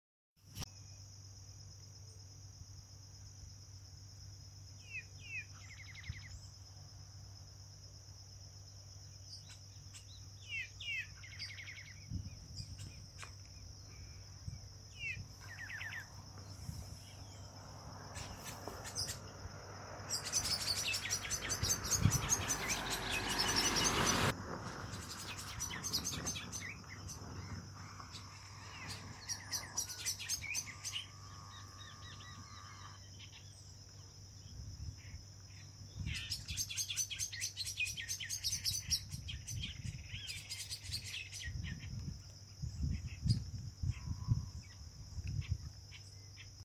Pepitero Gris (Saltator coerulescens)
Localización detallada: Colonia Ayuí, Paso del Águila
Condición: Silvestre
Certeza: Observada, Vocalización Grabada
Pepitero-gris-1_1_1.mp3